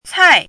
cài
国际音标：tsʰĄi˨˩˦;/tsʰĄi˥˧
cài.mp3